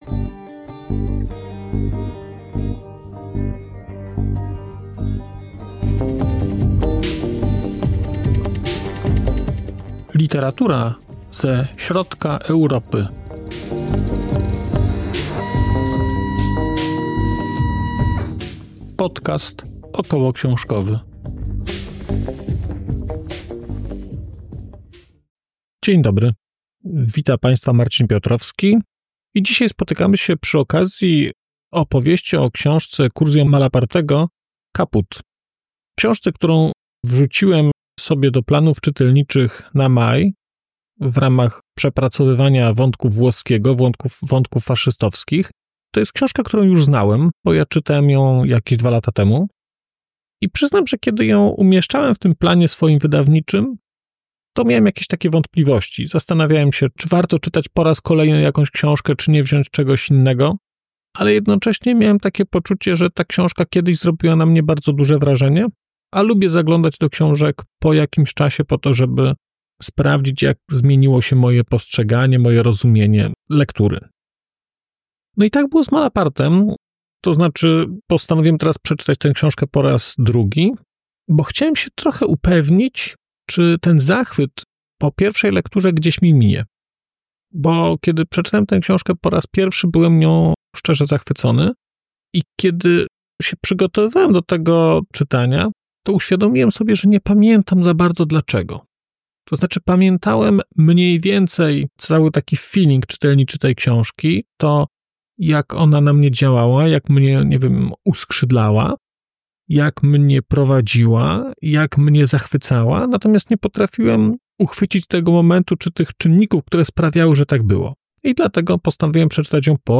🔧 odcinek zremasterowany: 8.04.2025